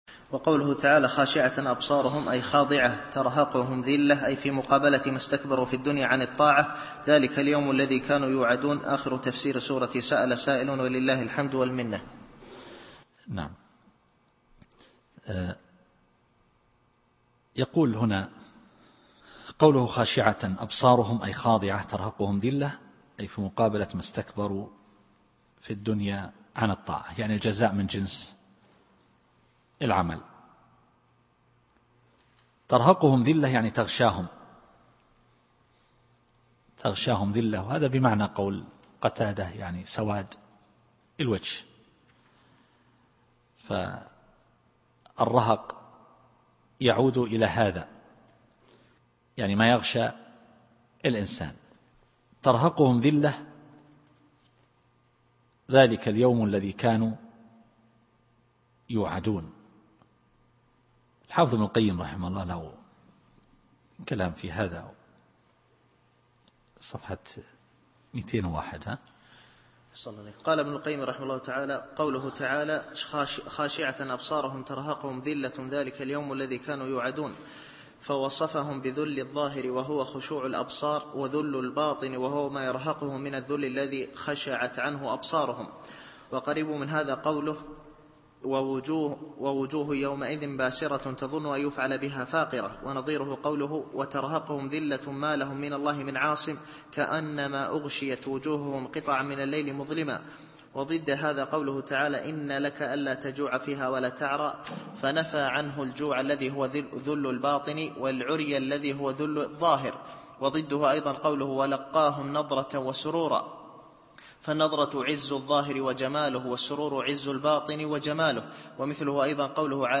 التفسير الصوتي [المعارج / 44]